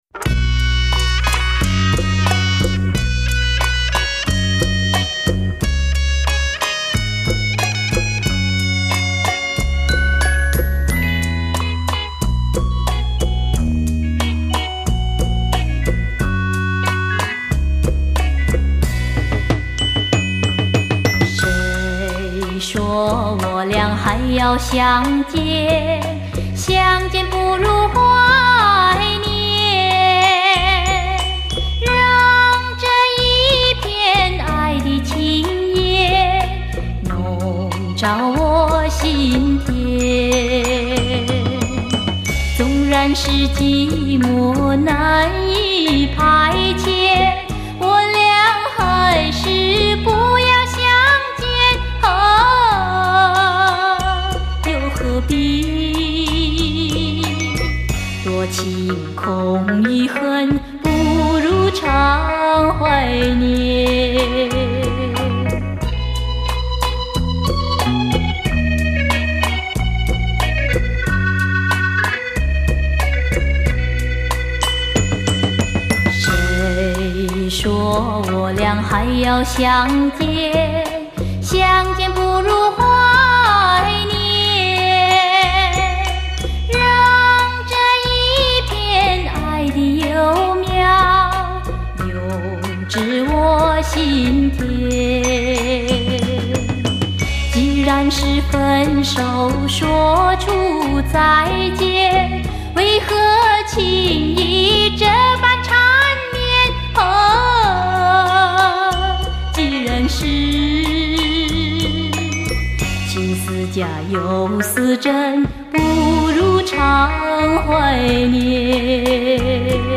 马来西亚华裔歌手